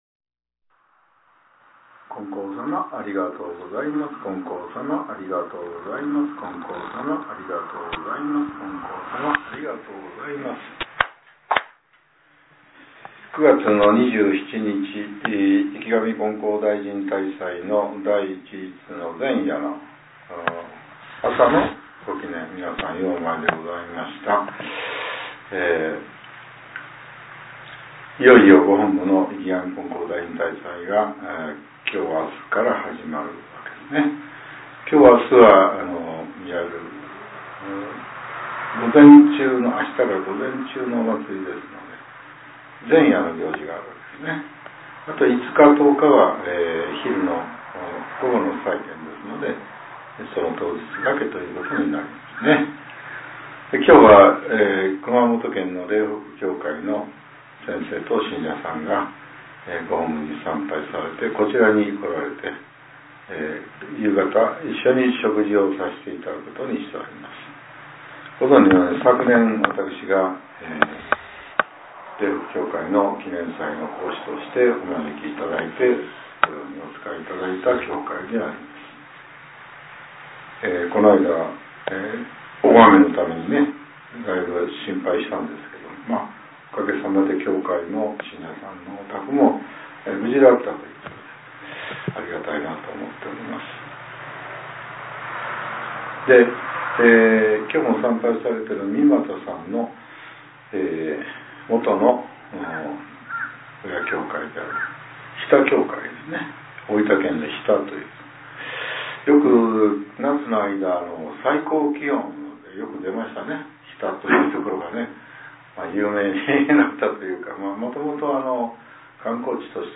令和７年９月２７日（朝）のお話が、音声ブログとして更新させれています。